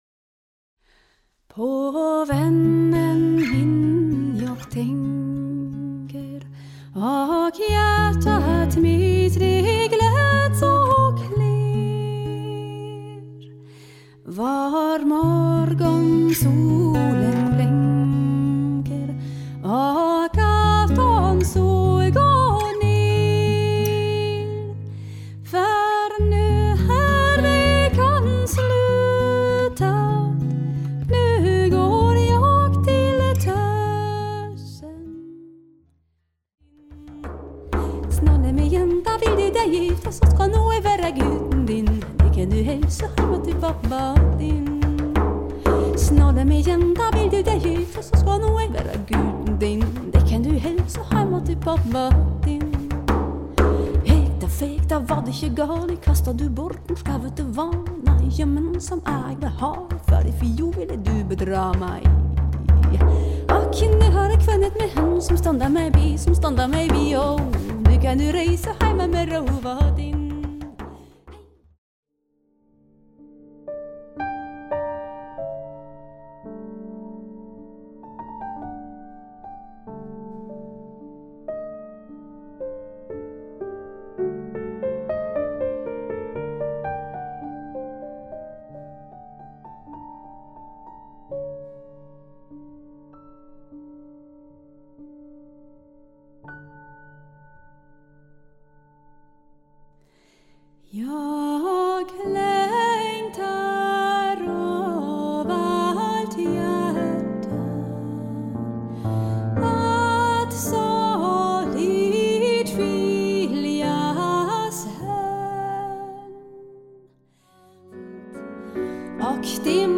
nordic urban folk
vocals/loop station
piano/guitar/frame drum